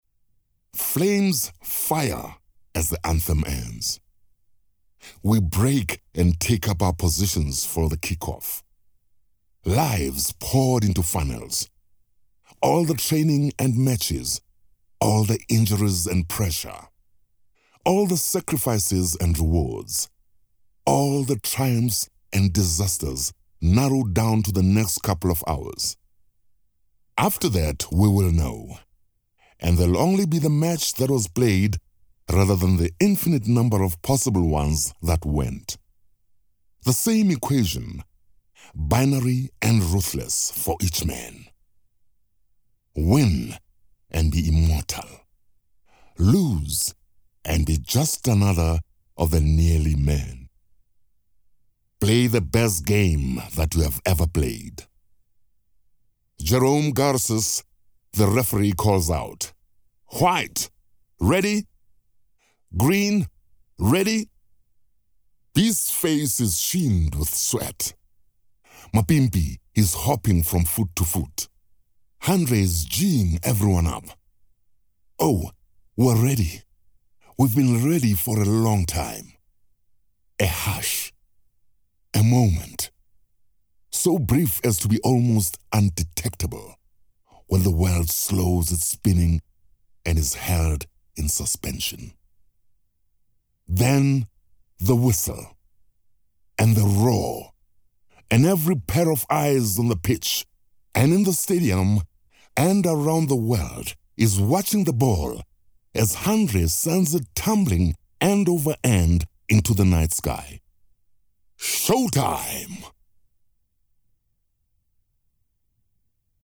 AUDIOBOOKS